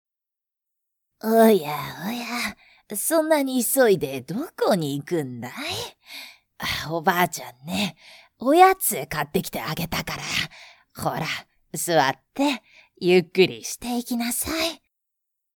With a bright and easy listening voice, specialize in high-tension advertising videos.
– Voice Actor –
Sweet Grandma